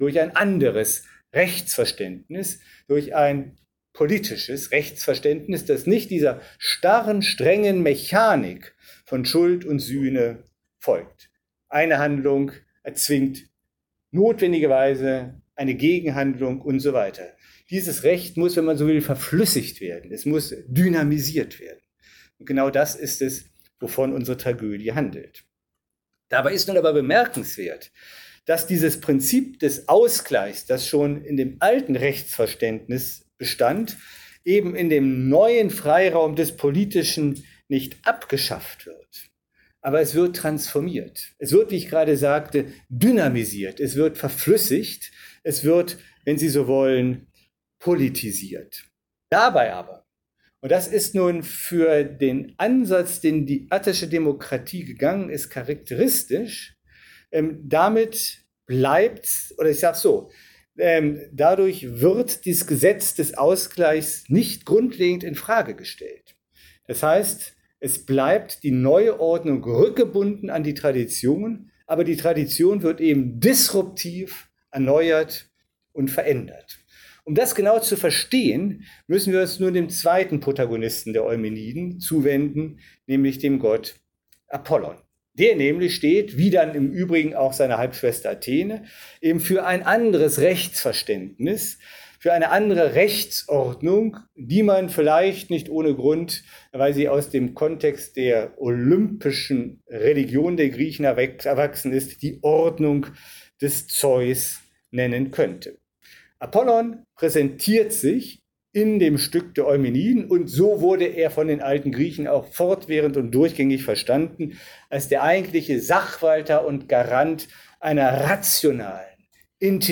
Ihr hört Teil 2 (Die Aufnahmen sind Vortragsmitschnitte aus dem Jahr 2021 aus Athen...)
Wesen_und_Ursprung_des_Politischen_Teil_2_-_Athen_2021.mp3